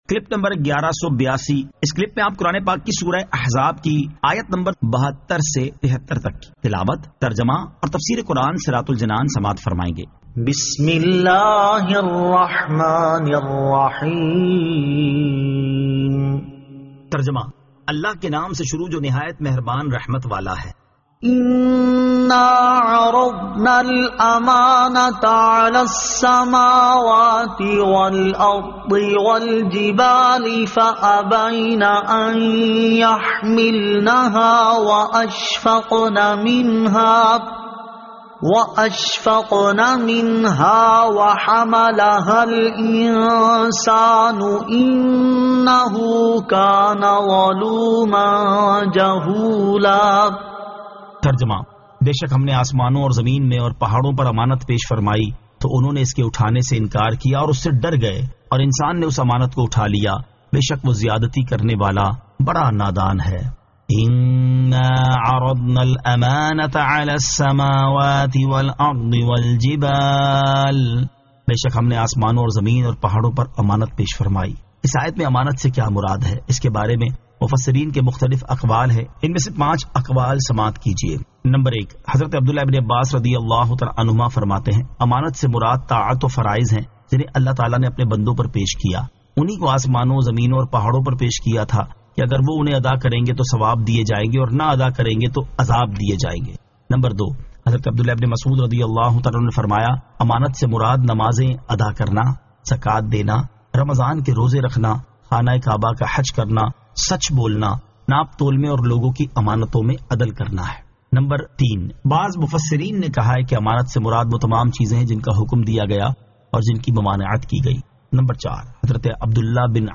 Surah Al-Ahzab 72 To 73 Tilawat , Tarjama , Tafseer